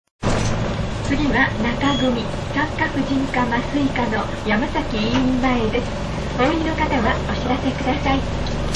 島鉄バスの車内放送は非常にユニークだった。
車内放送「